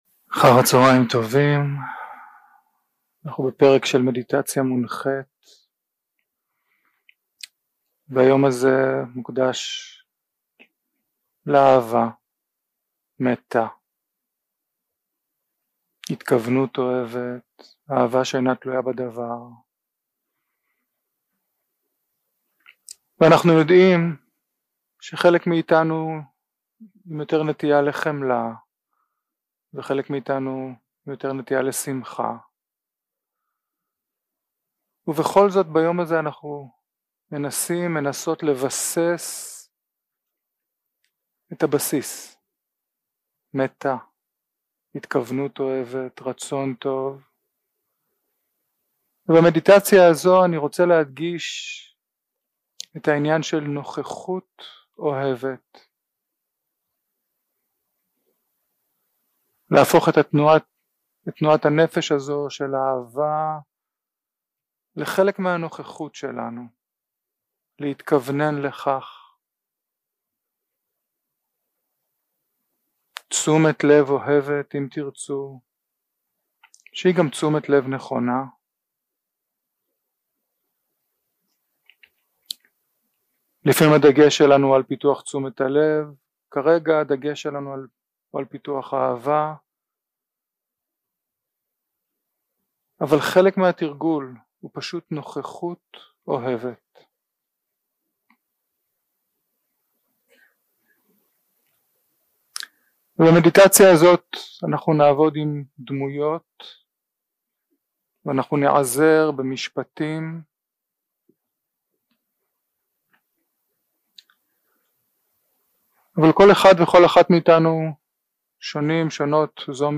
יום 2 – הקלטה 3 – צהריים – מדיטציה מונחית – מטא עם משפטים
יום 2 – הקלטה 3 – צהריים – מדיטציה מונחית – מטא עם משפטים 0:00 0:00 סוג ההקלטה: Dharma type: Guided meditation שפת ההקלטה: Dharma talk language: Hebrew